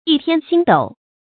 一天星斗 注音： ㄧˋ ㄊㄧㄢ ㄒㄧㄥ ㄉㄡˇ 讀音讀法： 意思解釋： 比喻事情多而雜亂。